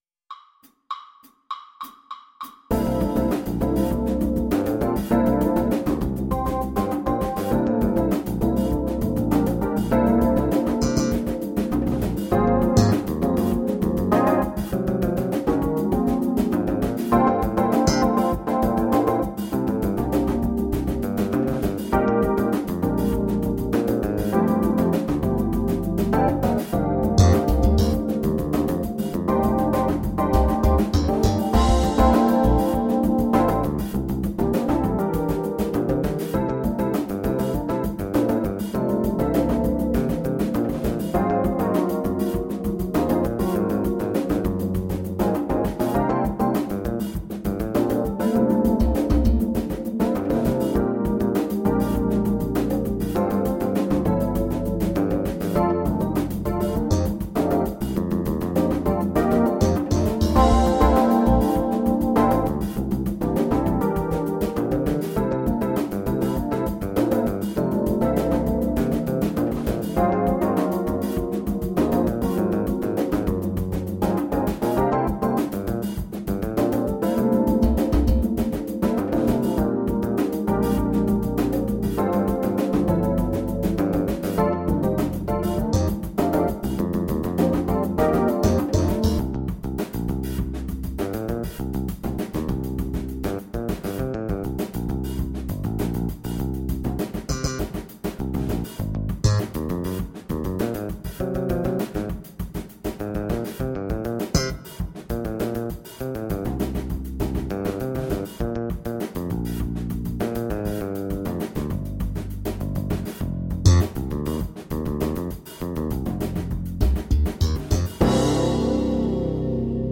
GUITAR
Guitar Etude A-2 Funk
Guitar Etude A-2 Funk Track
Guitar Playalong_NMEAJazzSetA2(FUNK).mp3